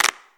• Old School Hip-Hop Clap One Shot B Key 01.wav
Royality free clap single shot - kick tuned to the B note. Loudest frequency: 3619Hz
old-school-hip-hop-clap-one-shot-b-key-01-pBO.wav